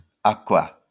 Acoua (French pronunciation: [akwa]